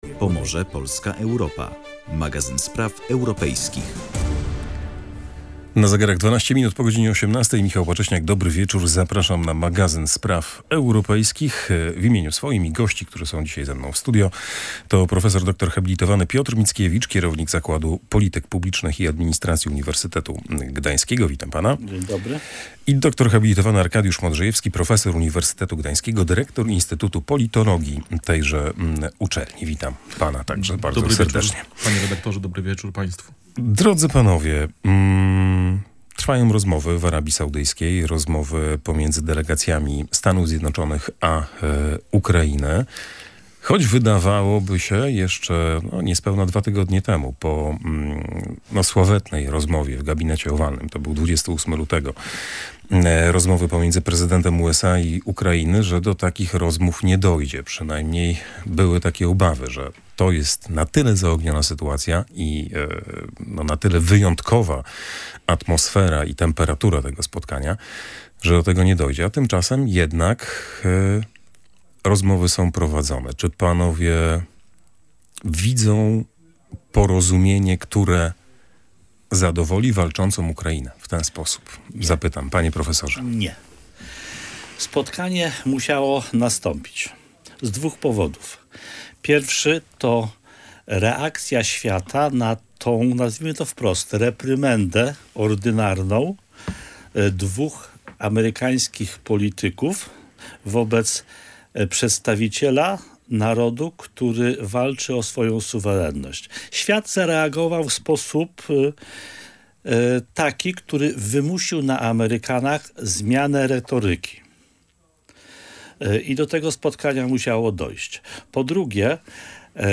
O bezpieczeństwie Europy w kontekście zapowiedzi USA dotyczącej zmniejszenia swojej obecności militarnej na naszym kontynencie rozmawiali goście audycji „Pomorze, Polska, Europa”